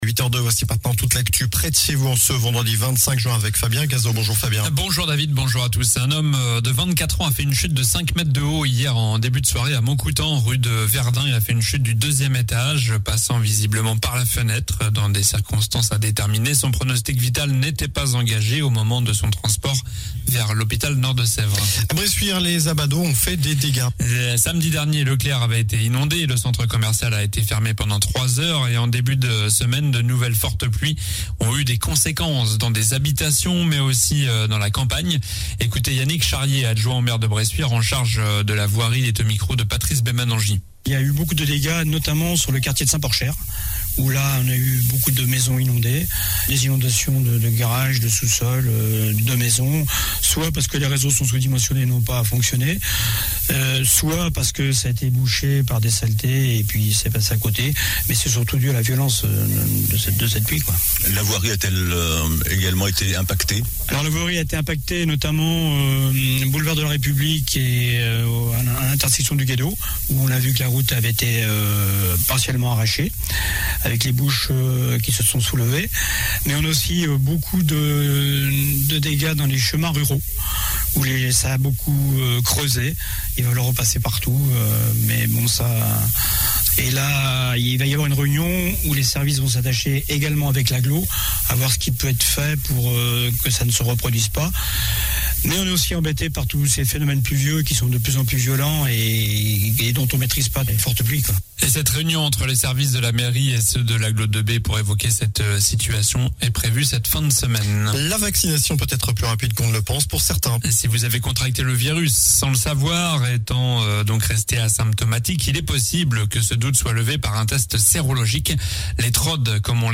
Journal du vendredi 25 juin (matin)